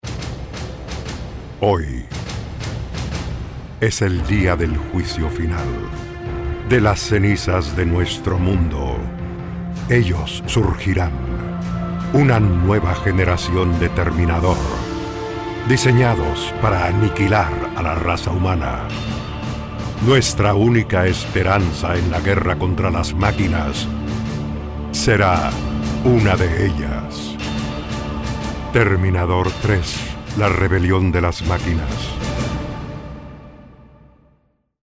Terminador 3 Trailer Usuario PC: Clic izquierdo en icono Saturno para oír audio en modalidad "streaming".